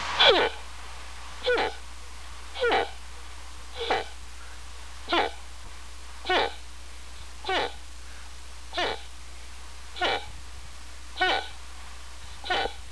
Wildlife Sounds
moose3.wav